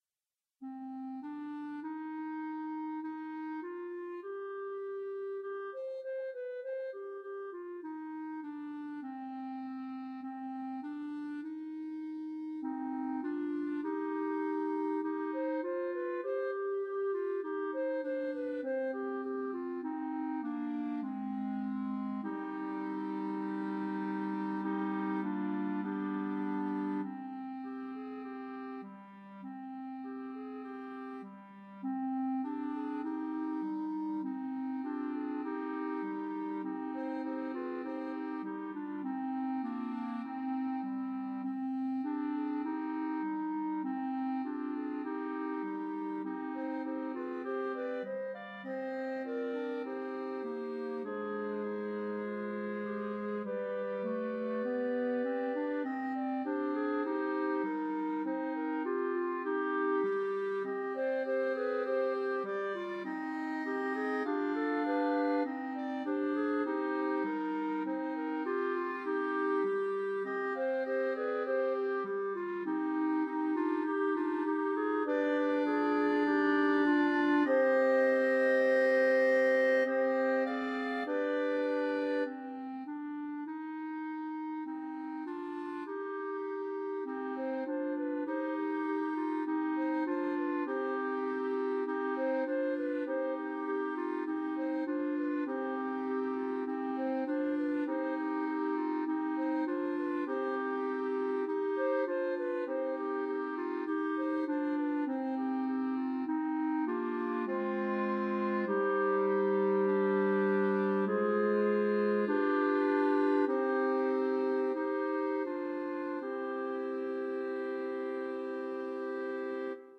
Well-known folk song
for Clarinet Quartet